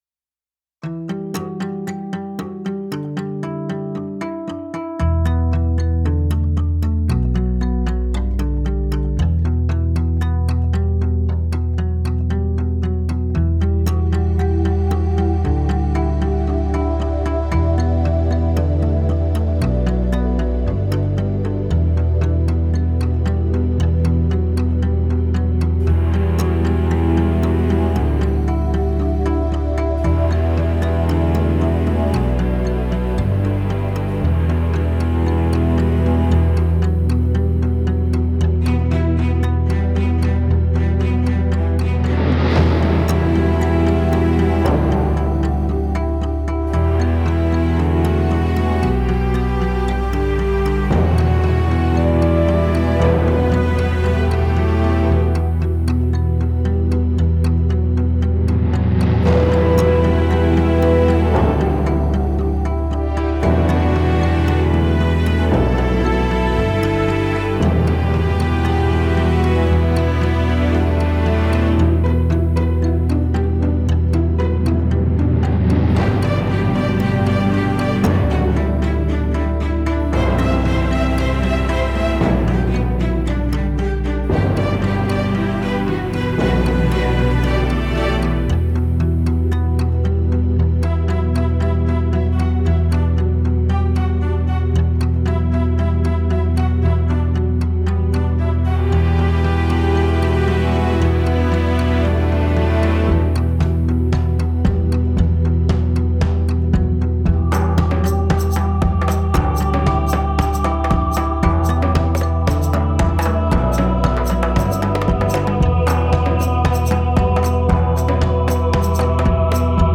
Baobhan Sith (Soundtrack)
Испытал свои силы в киношной музыке.